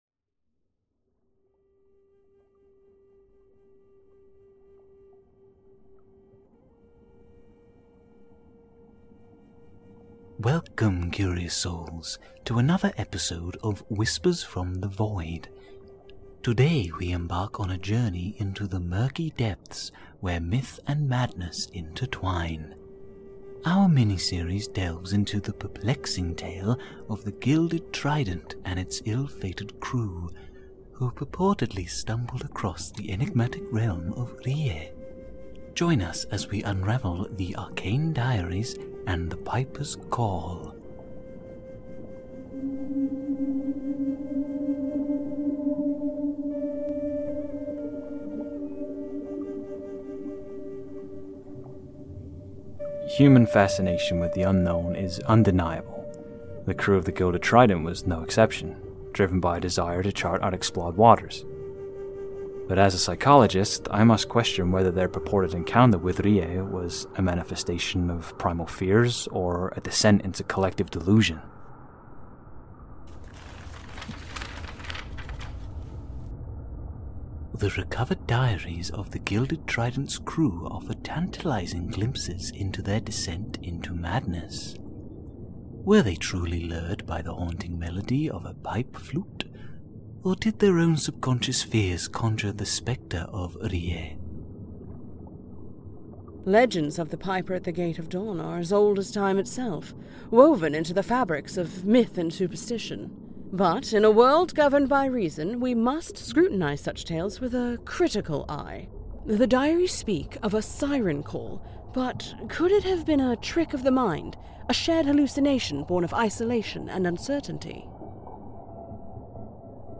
Play Rate Apps Listened List Bookmark Share Get this podcast via API From The Podcast Whispers From the Void Whispers from the Void is an immersive audio drama that plunges listeners into a world where the boundaries between reality and the supernatural are blurred. Follow the gripping tale of Richard Harrison and Jason Vasquez as they unravel the dark secrets hidden within their city, confronting eldritch horrors and ancient cults that threaten their sanity and existence.